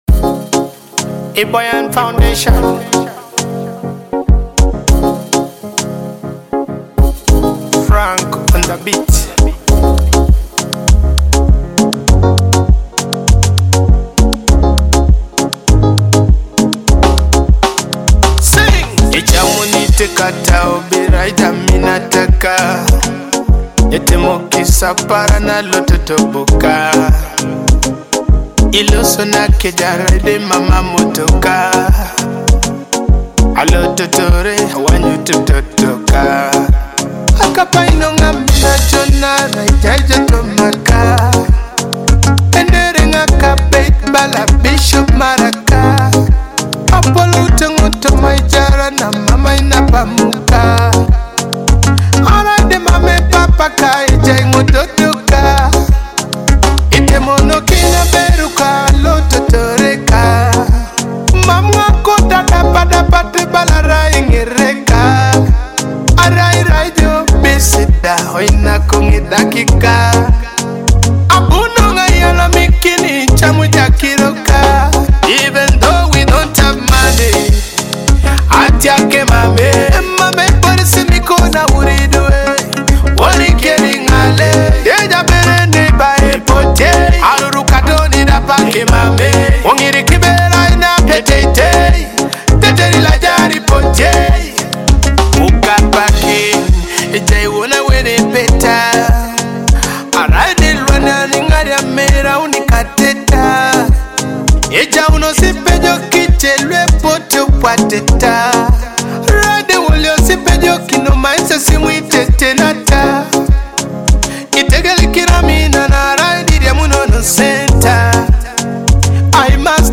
a heartfelt Teso love song